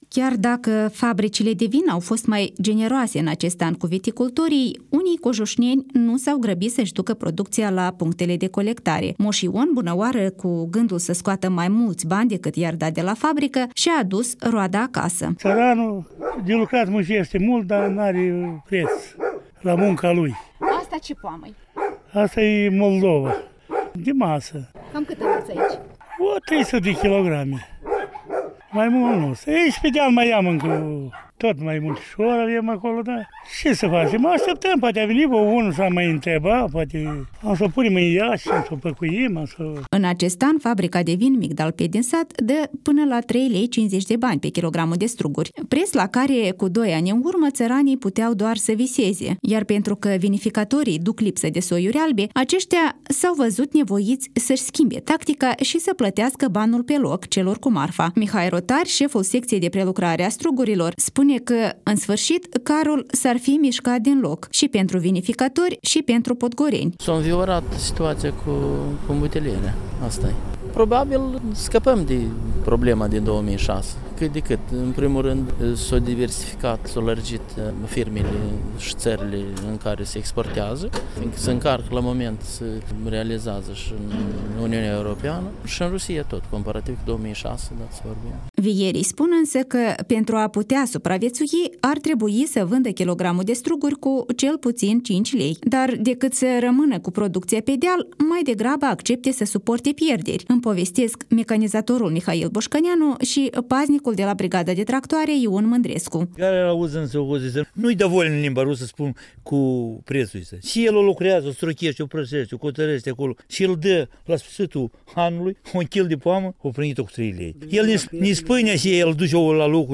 Omul s-a plîns că dacă nu va vedea şi mai departe rostul să cultive viţa-de-vie, cel mai probabil va renunţa la podgoriile sale, cum de altfel mi-au spus şi alţi cojuşneni tentaţi de activităţi „mai bănoase” sau de plecatul peste hotare.